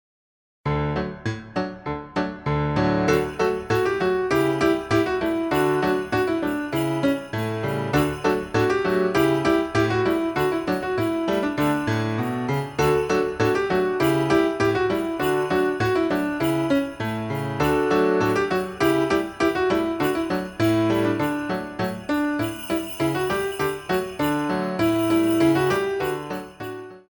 Glissé Degage